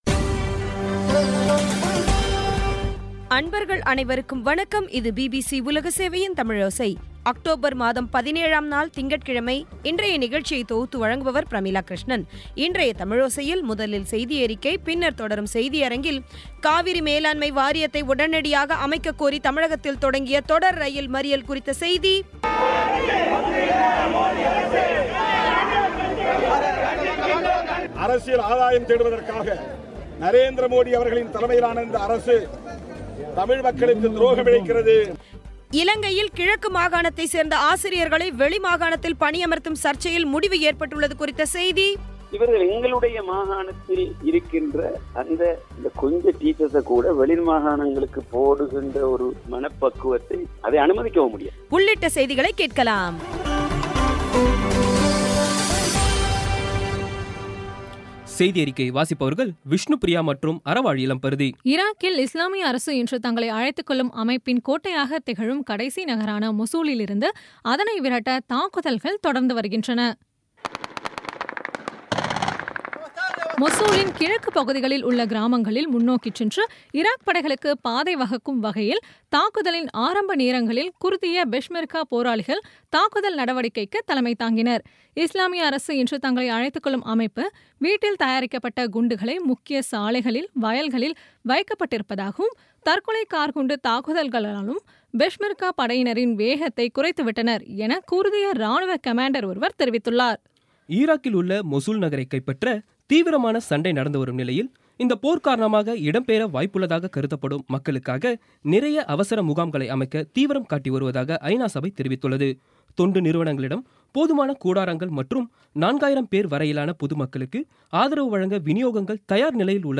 இன்றைய தமிழோசையில், முதலில் செய்தியறிக்கை, பின்னர் தொடரும் செய்தியரங்கத்தில்,